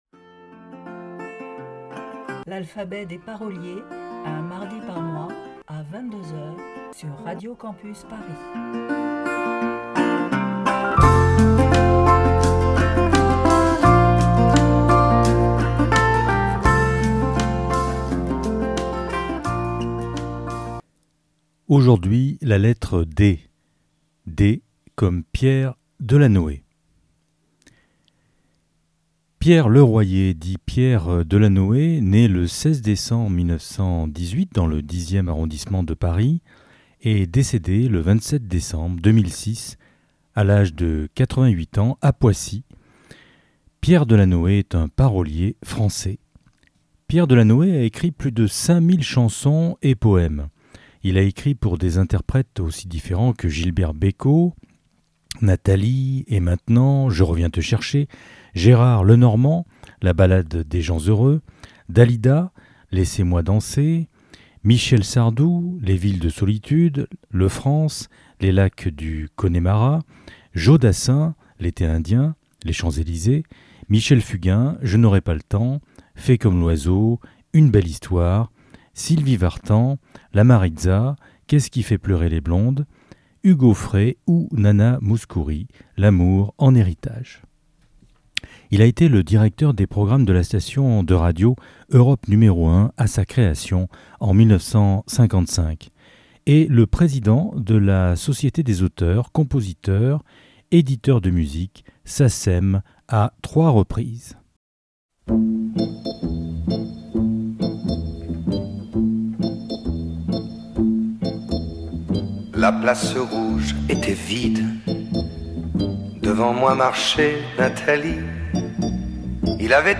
Magazine Pop & Rock